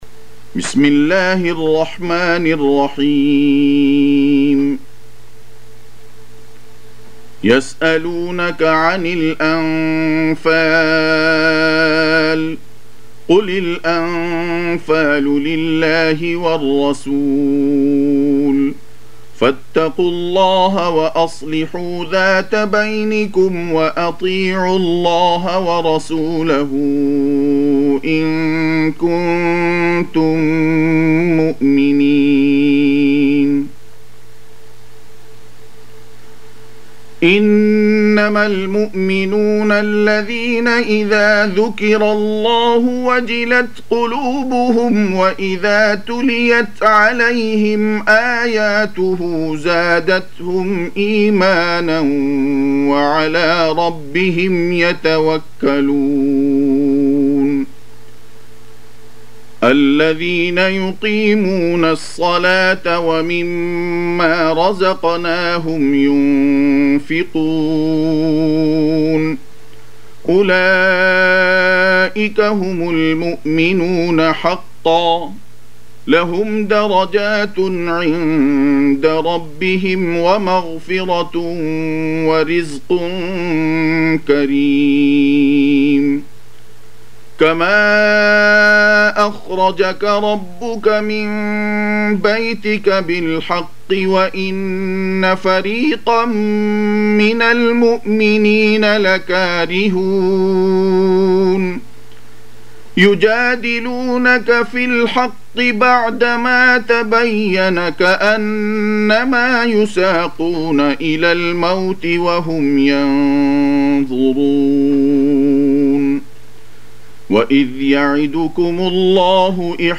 Surah Sequence تتابع السورة Download Surah حمّل السورة Reciting Murattalah Audio for 8. Surah Al-Anf�l سورة الأنفال N.B *Surah Includes Al-Basmalah Reciters Sequents تتابع التلاوات Reciters Repeats تكرار التلاوات